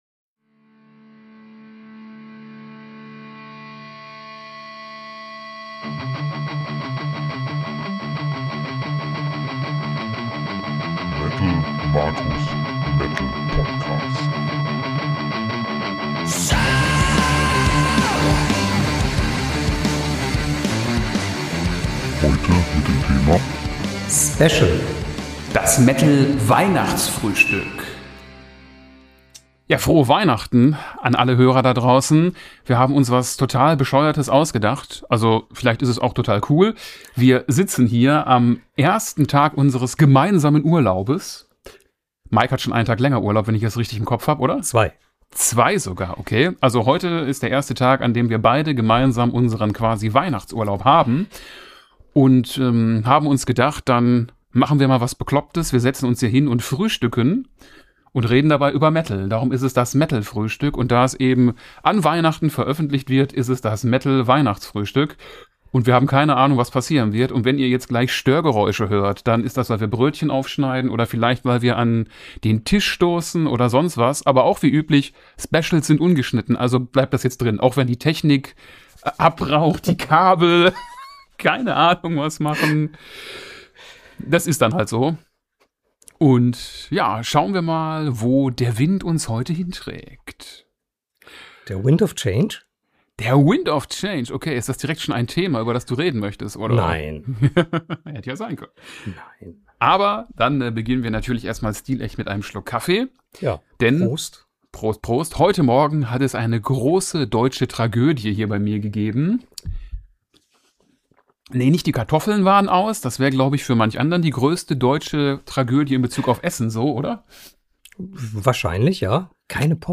Egal ... wir treffen uns, frühstücken gemeinsam und nehmen auf, was dann passiert.